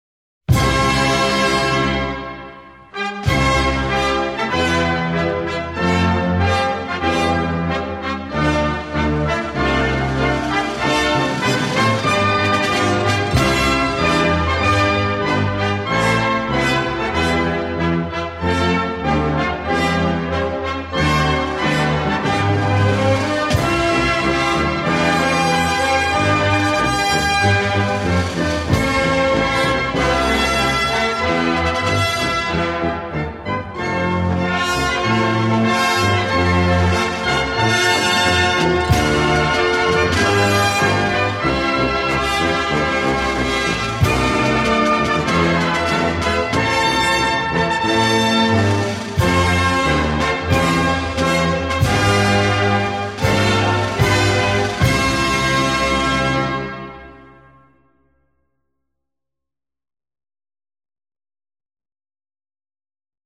Selbst mit Hilfe vergeteilter deutscher Texte wird nur widerwillig mitgesungen.
nationalhymne.mp3